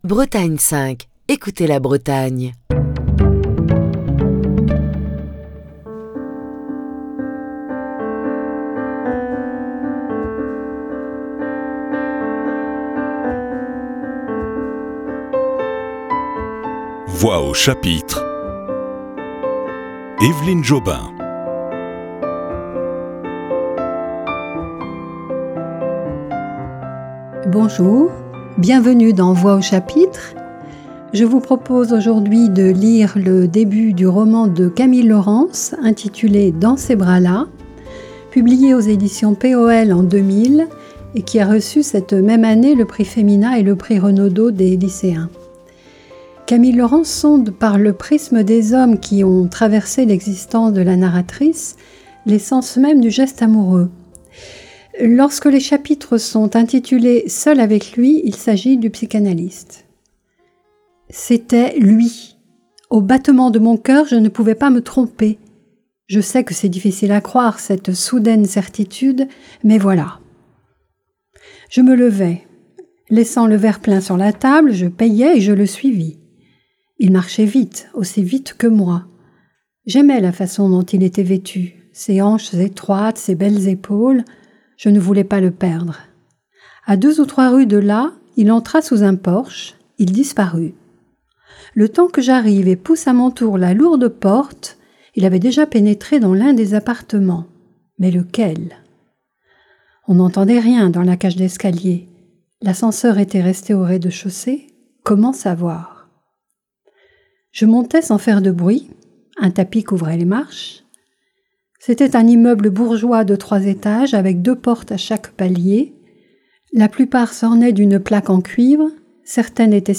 lit le début du roman de Camille Laurens, intitulé Dans ces bras-là, publié aux éditions P.O.L en août 2000. Ce roman de Camille Laurens a reçu le prix Femina et le Prix Renaudot des lycéens.